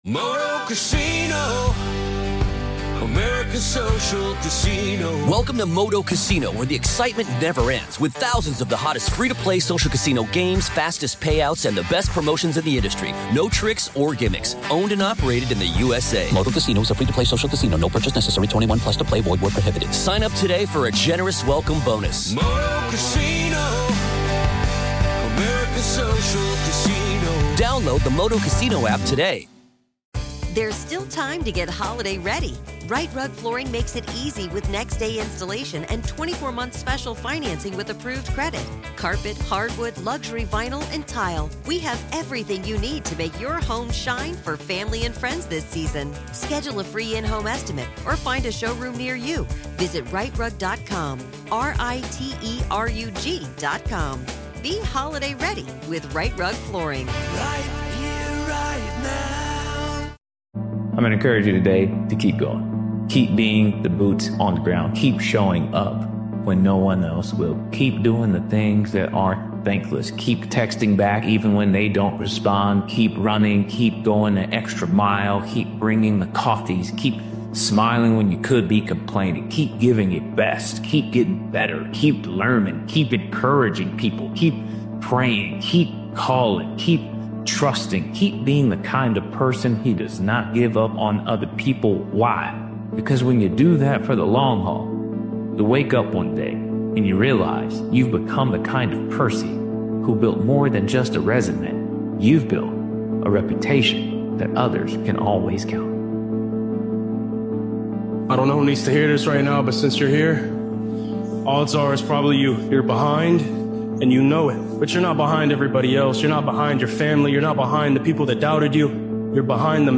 This powerful motivational speeches compilation is a reminder that true strength lies in keeping promises to yourself, even when motivation fades. Discipline turns words into action, and consistency transforms action into results.